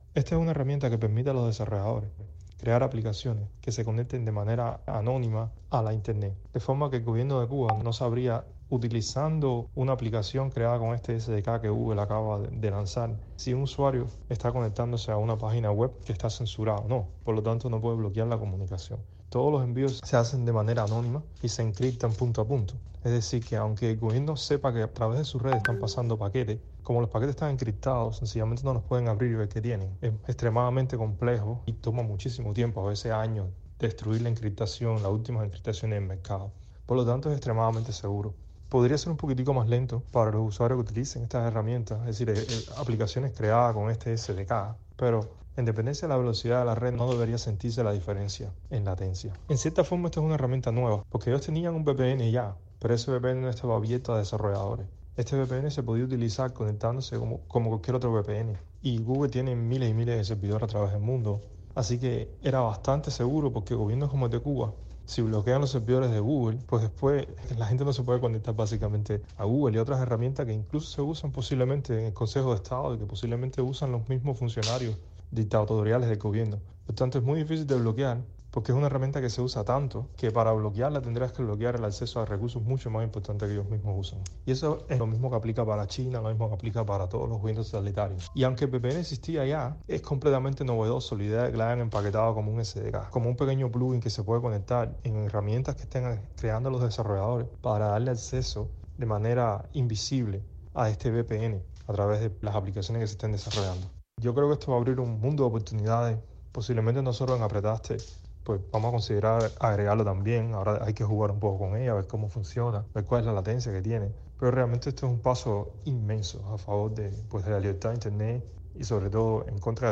Google actualizó una herramienta que ayudará a combatir la censura en Internet de gobiernos totalitarios, incluido el de Cuba. Un experto explicó a Martí Noticias cómo funciona.